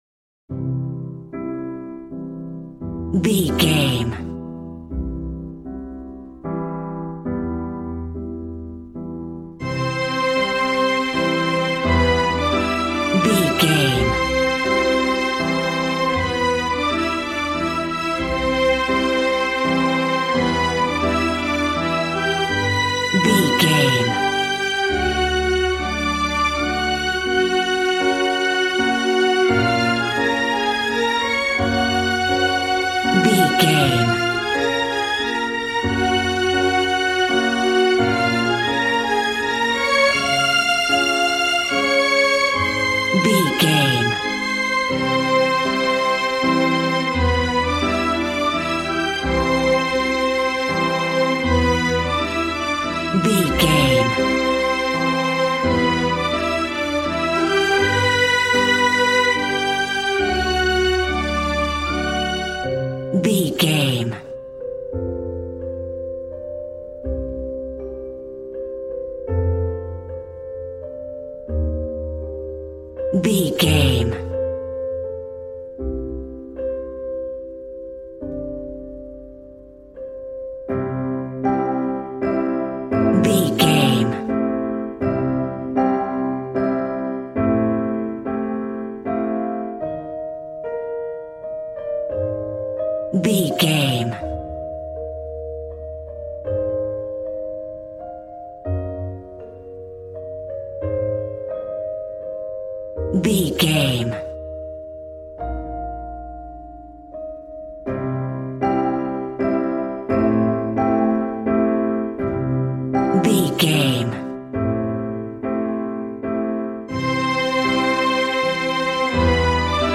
Regal and romantic, a classy piece of classical music.
Ionian/Major
regal
strings
violin